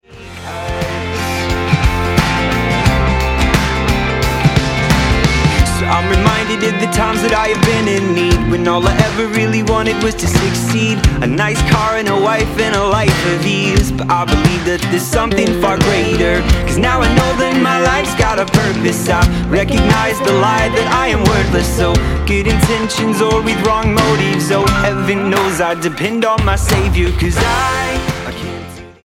Husband and wife duo
Folk-tinged acoustic pop with a touch of soul
Style: Roots/Acoustic